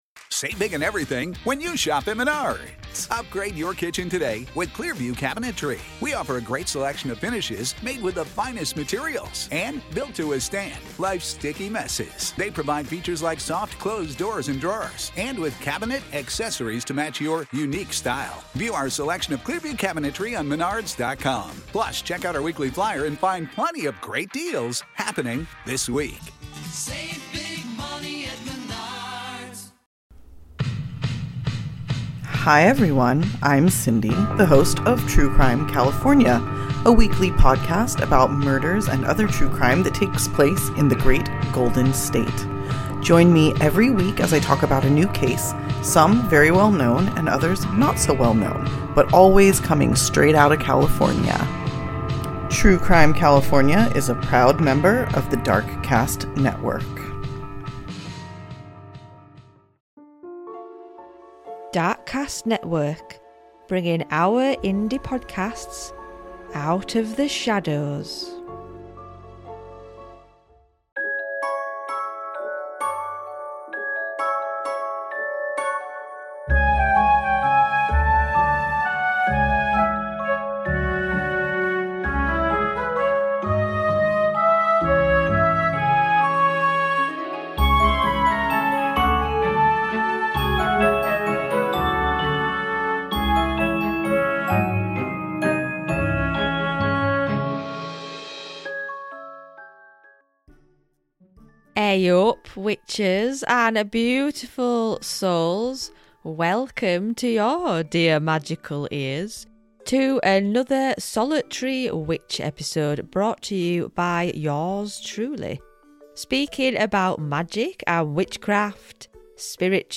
Humorous interjections from friends add warmth.